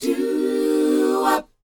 DOWOP F EU.wav